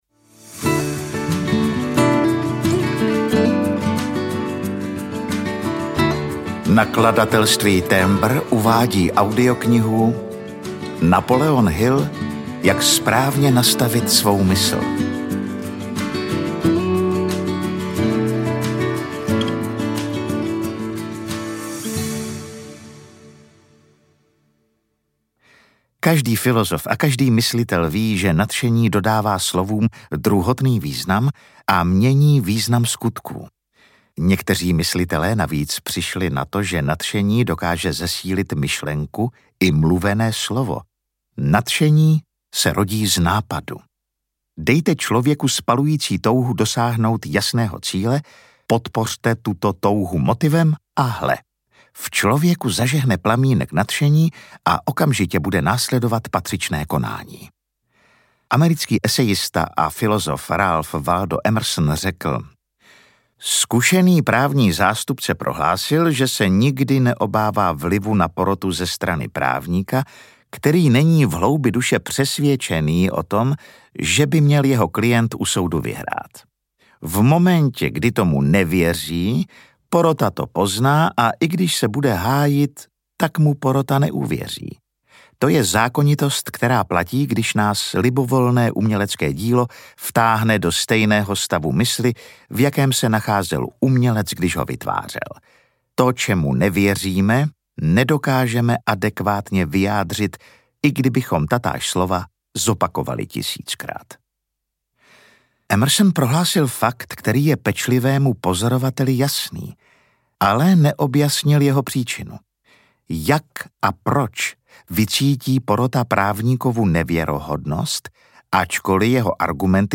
Ukázka z knihy
jak-spravne-nastavit-svou-mysl-audiokniha